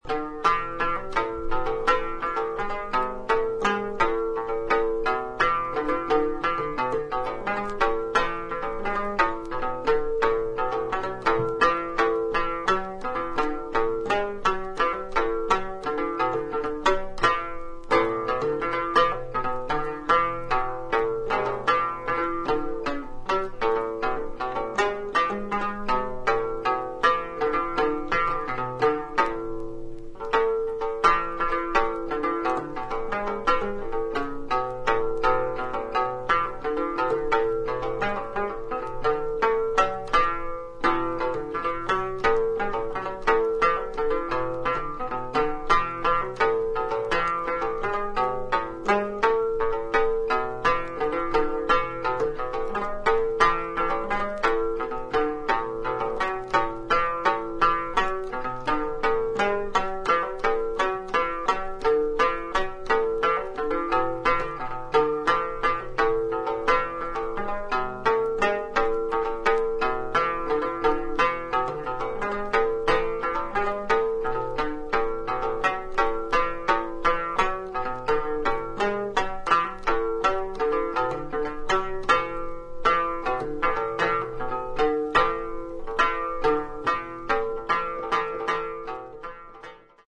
B1 Tibetan Dramnyen, 6-stringed Lute